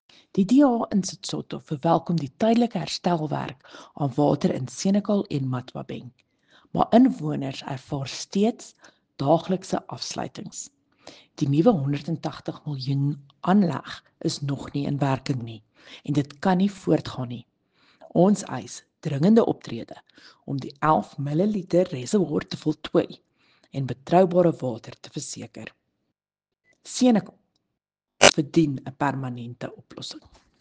Afrikaans soundbites by Cllr Riëtte Dell and